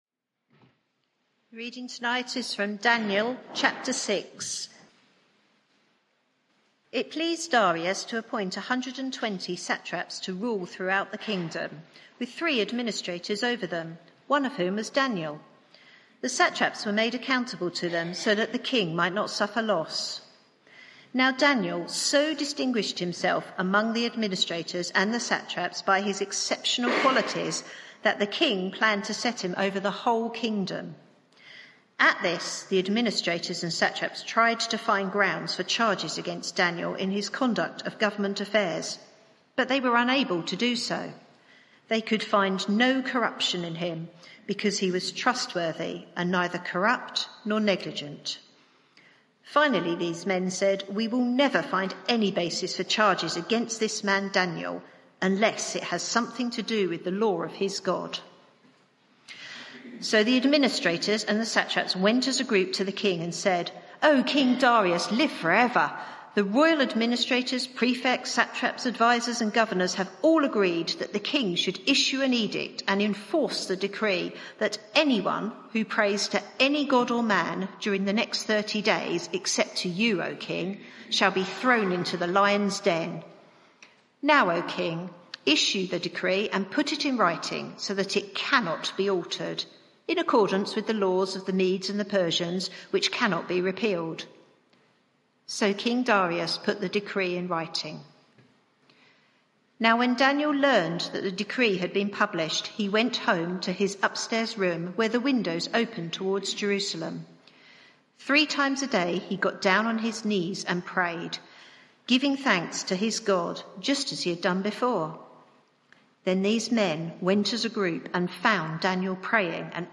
Media for 6:30pm Service on Sun 11th Jun 2023 18:30 Speaker
Sermon